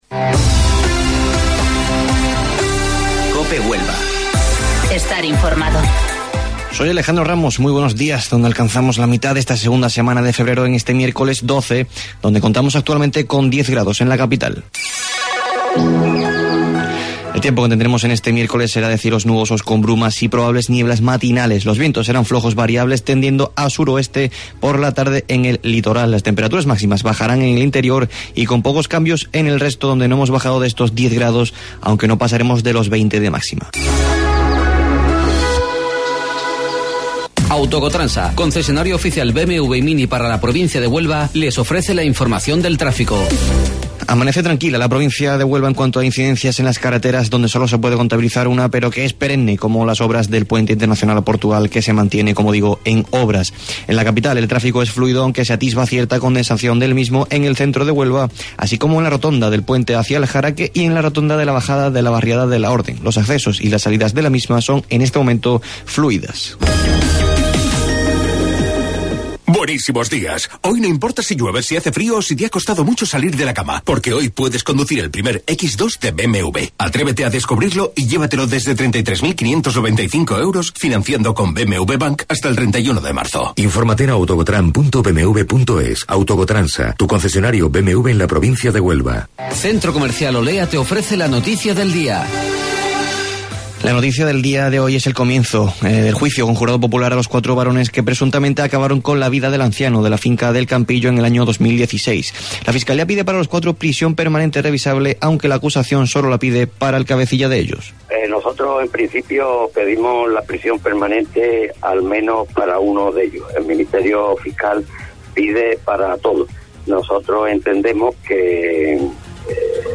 AUDIO: Informativo Local 08:25 del 12 de Febrero